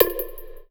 FX (EDM).wav